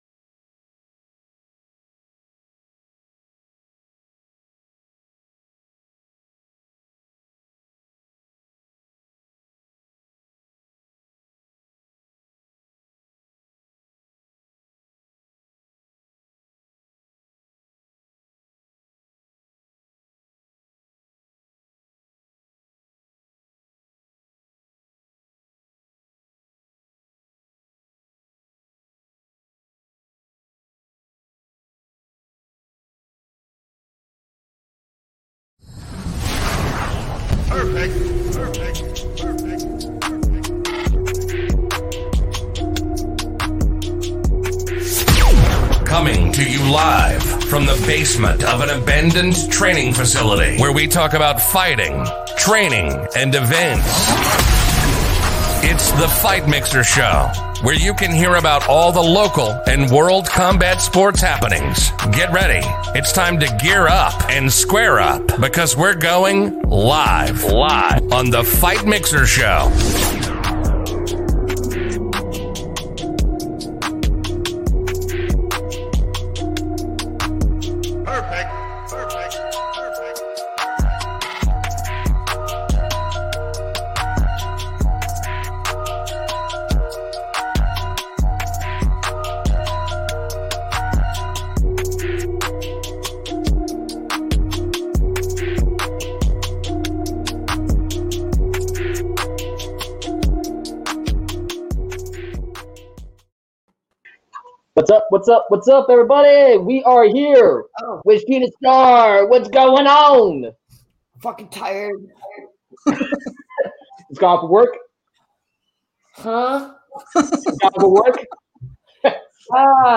Interview - Fight Mixer